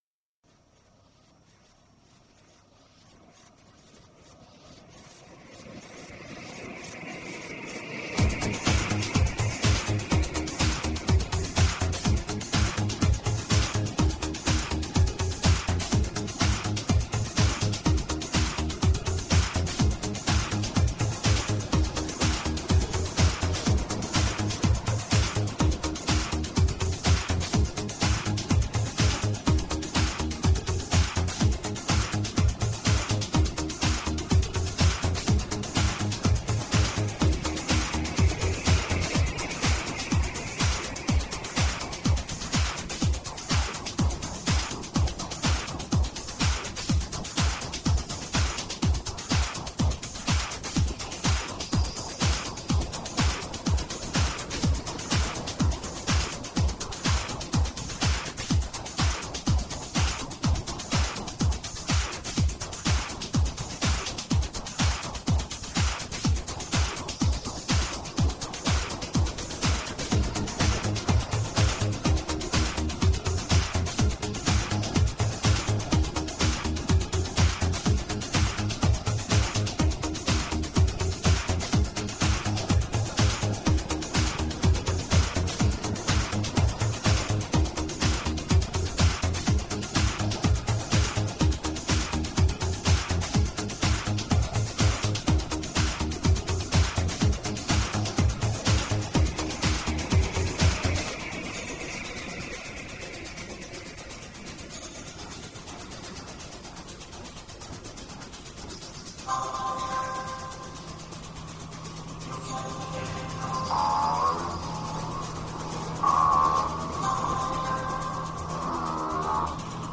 Upbeat underground with shades of new wave.
Tagged as: Electronica, Other, Hard Electronic, IDM